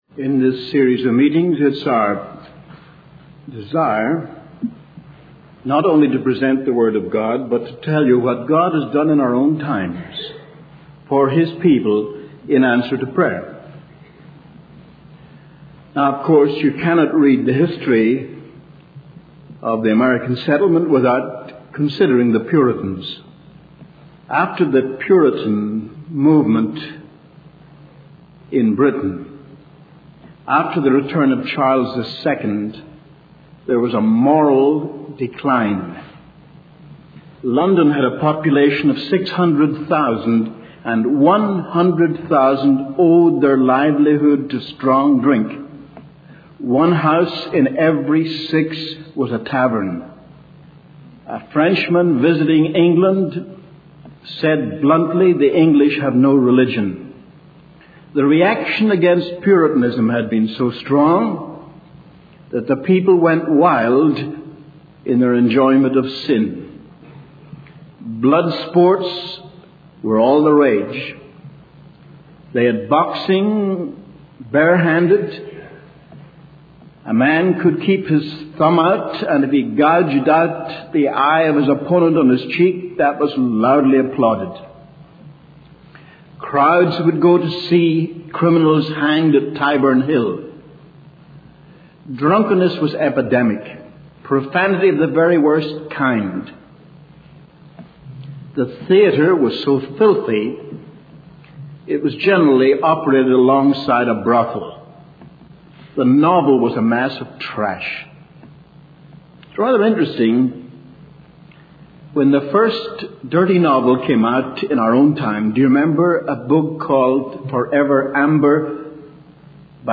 In this sermon, the preacher discusses the preaching of John Wesley and George Whitfield in Britain during the 18th century.